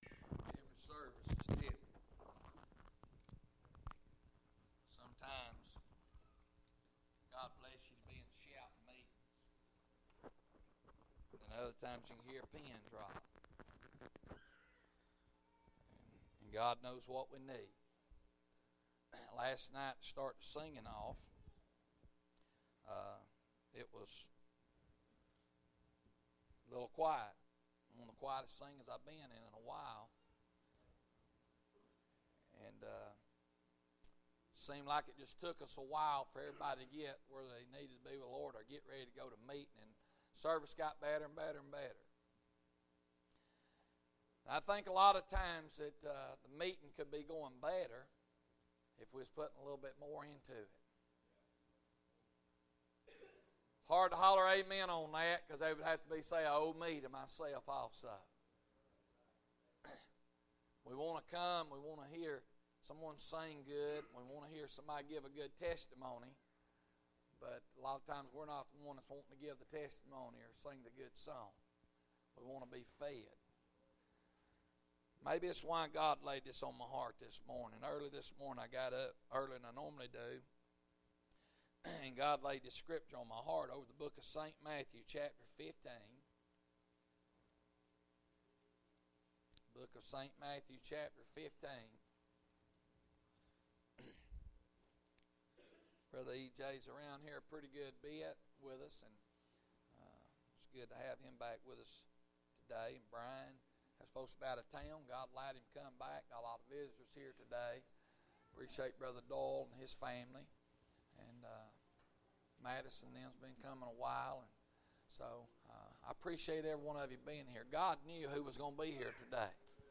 Sermon media